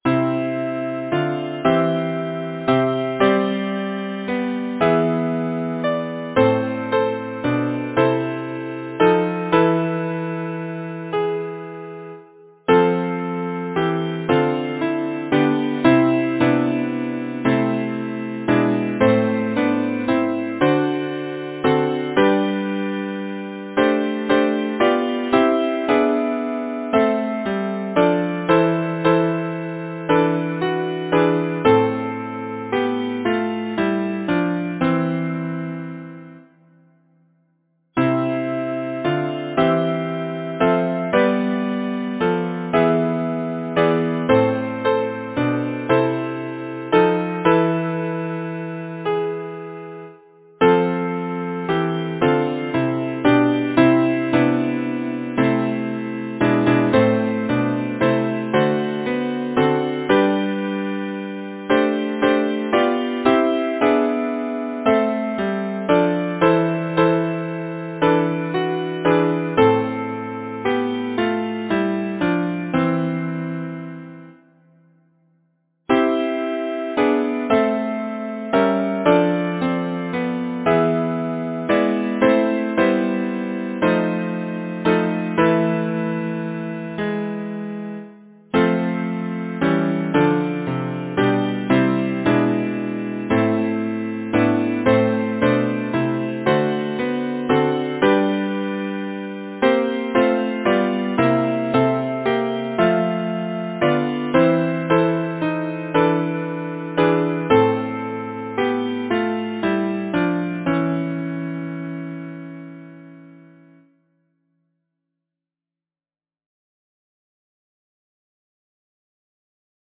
Title: Once I was young Composer: Arthur Somervell Lyricist: Traditional Number of voices: 4vv Voicing: SATB Genre: Secular, Partsong, Folksong
Languages: English, Manx Instruments: A cappella
First published: 1912 Boosey & Co. Description: Manx folksong